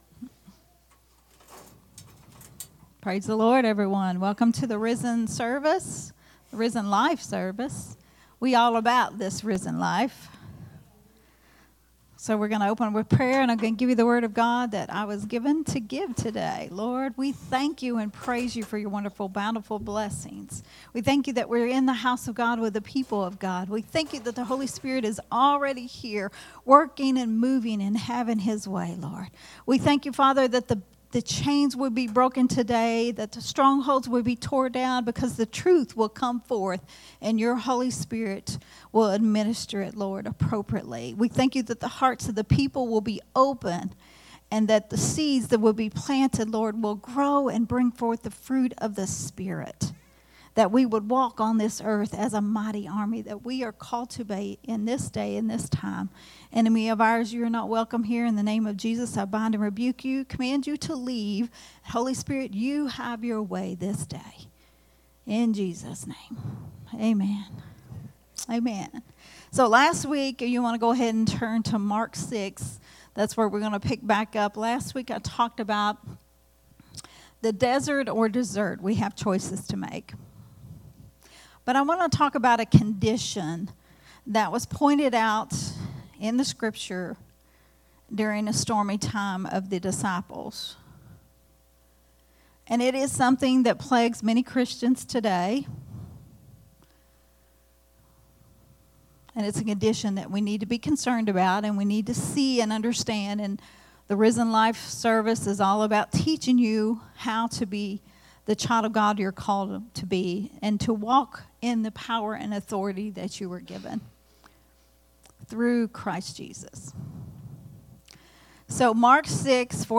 a Sunday Morning Risen Life teaching
recorded at Unity Worship Center on October 6th, 2024.